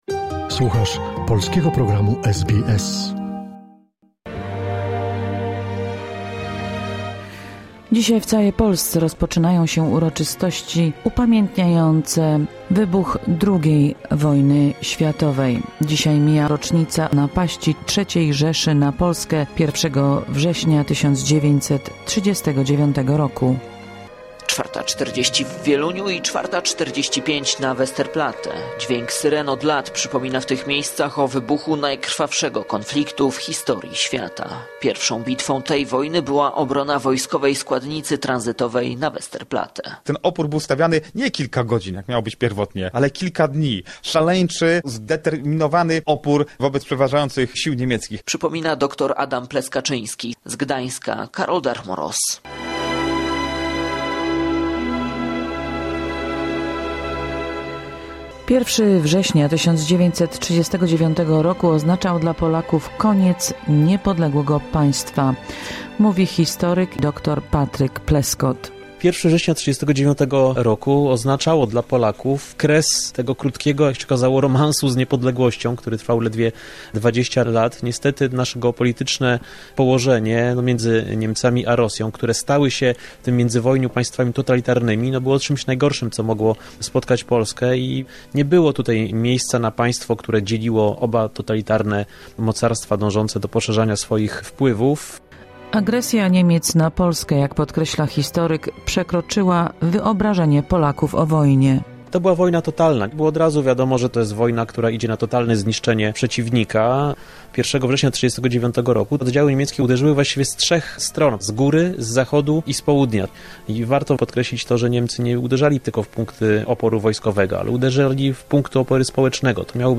Wypowiedzi historyka, wspomnienia żołnierzy i świadków pierwszych godzin tego co pózniej przerodziło się w największy konflikt zbrojny w dziejach ludzkości…